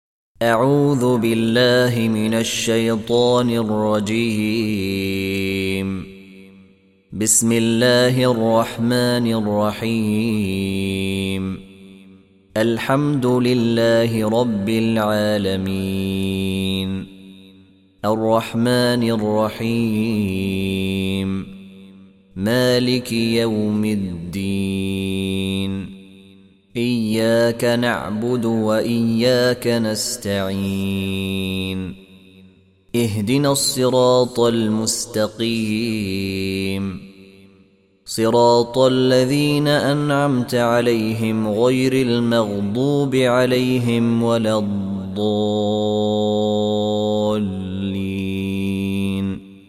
Surah Repeating تكرار السورة Download Surah حمّل السورة Reciting Murattalah Audio for 1. Surah Al-F�tihah سورة الفاتحة N.B *Surah Includes Al-Basmalah Reciters Sequents تتابع التلاوات Reciters Repeats تكرار التلاوات